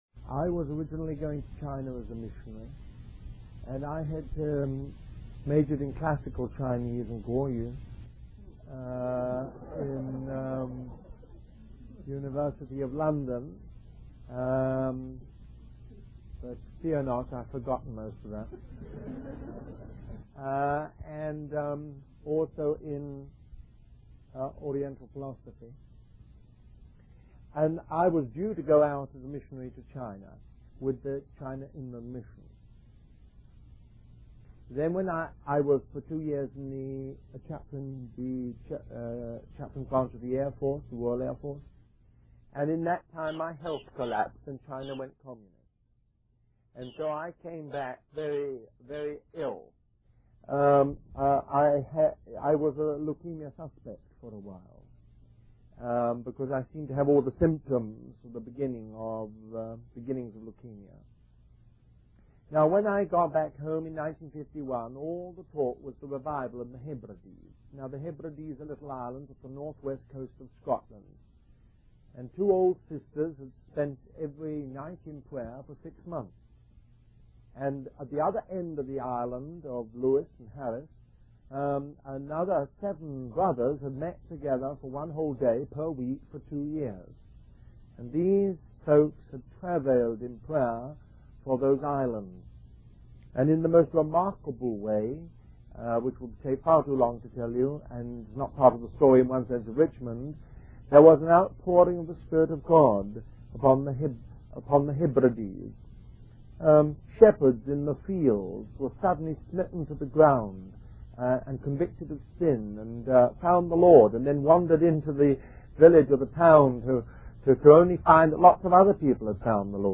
A collection of Christ focused messages published by the Christian Testimony Ministry in Richmond, VA.
Christian Family Conference